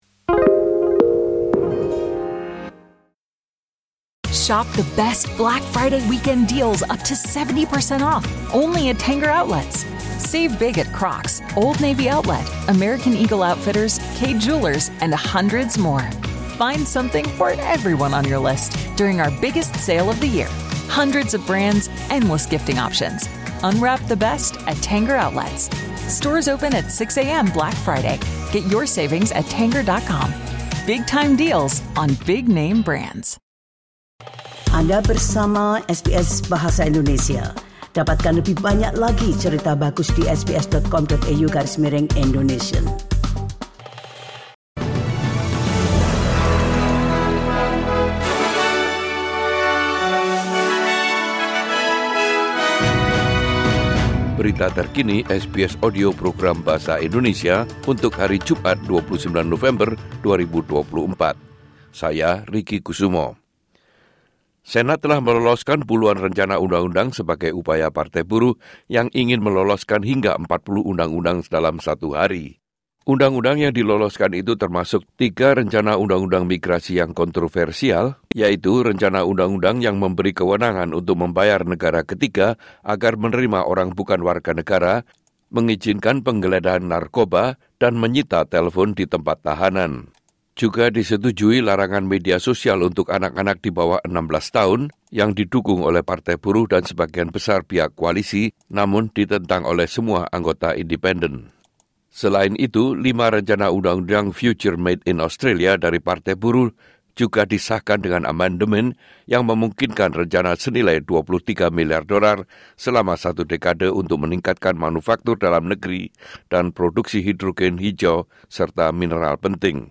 Berita Terkini SBS Audio Program Bahasa Indonesia - 29 November 2024